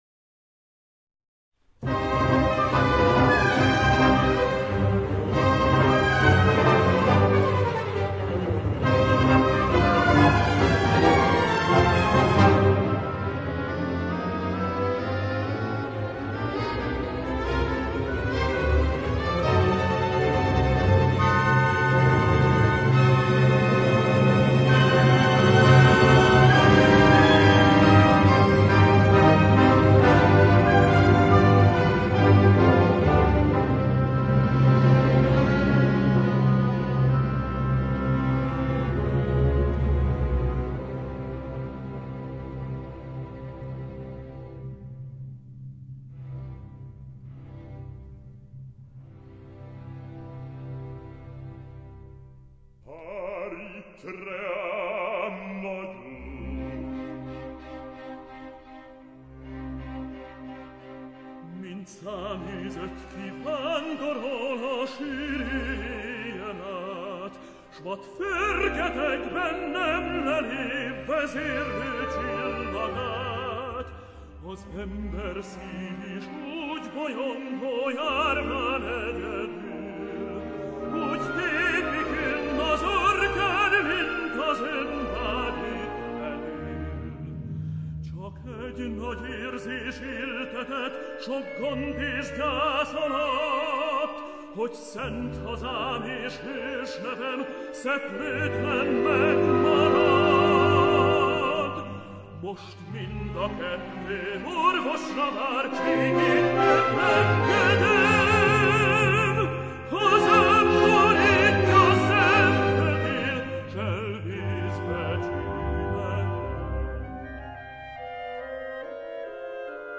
No.7 Aria&Duet
LO: Tape or FM quality (64 kbps) mp3, full length